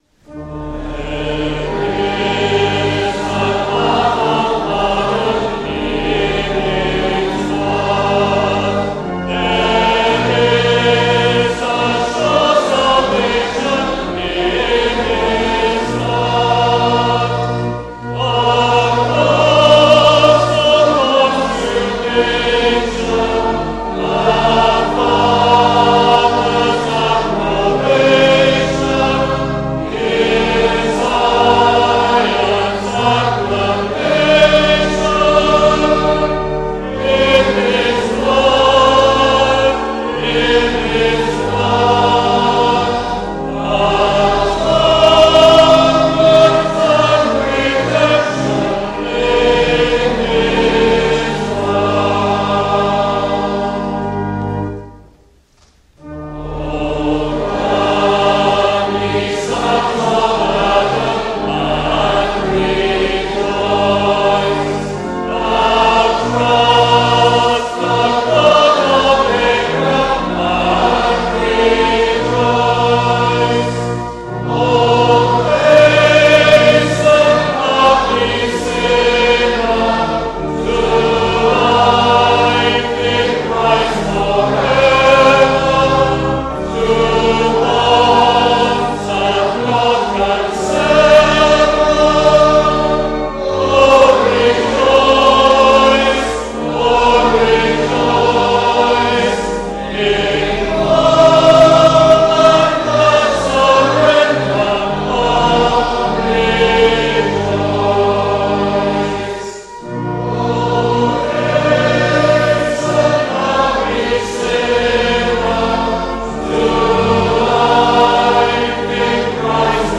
AudioTrack 18-Summer-Conference-Congregation-There-is-a-path-of-pardon.mp3